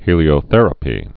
(hēlē-ō-thĕrə-pē)